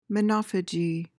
PRONUNCIATION:
(muh-NAH-fuh-jee)